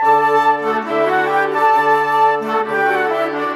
Rock-Pop 01 Winds 04.wav